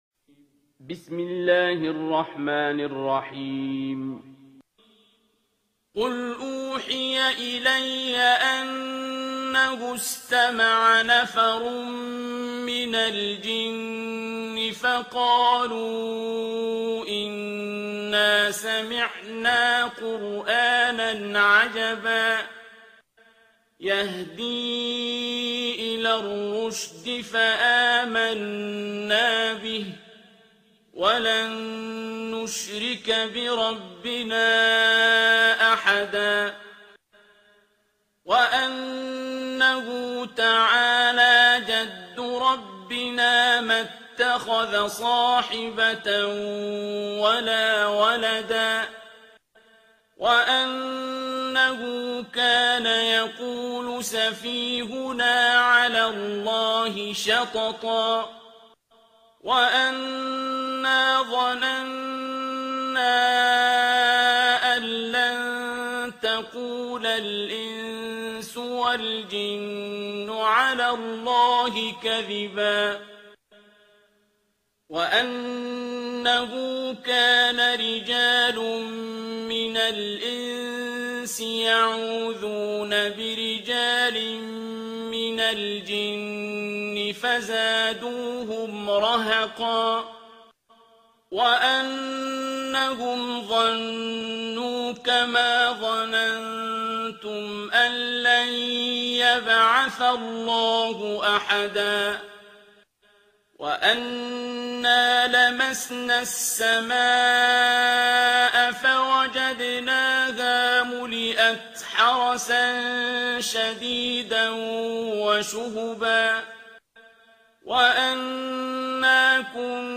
ترتیل سوره جن با صدای عبدالباسط عبدالصمد